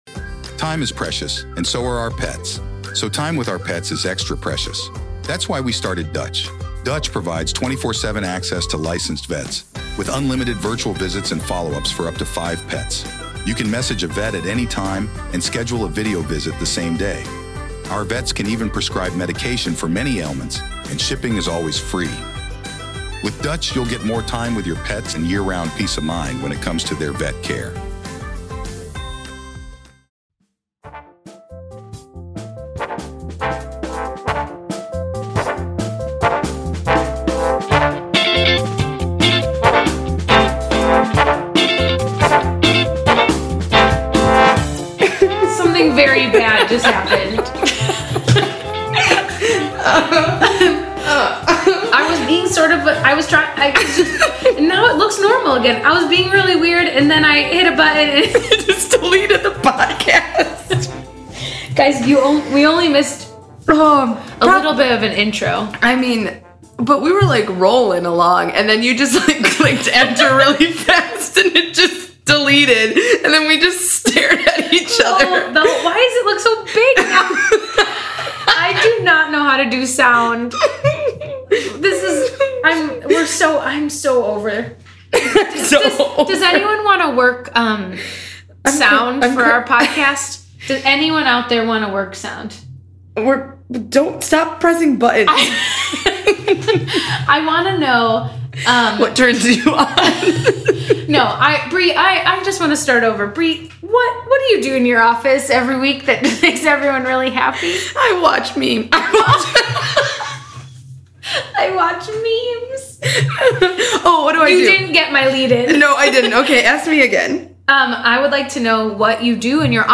Welcome back to your favorite Grey’s Anatomy podcast with your two favorite hosts who struggle with technology.
So, take a listen to an epic cold open of uncontrollable laughter at their failures.